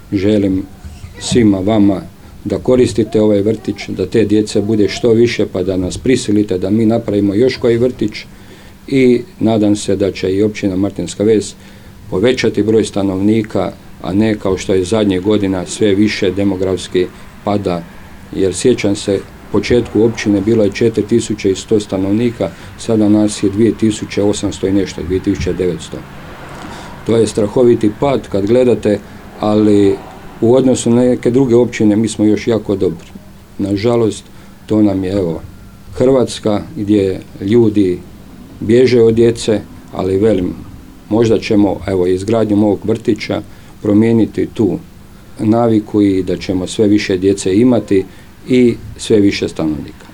Tako je nedavno svečano otvoren i prvi vrtić na području te općine, u naselju Strelečko.
Tom prigodom načelnik Stjepan Ivoš osvrnuo se na trenutnu demografsku sliku općine te izrazio želju da djece i mladih na području Martinske Vesi bude što više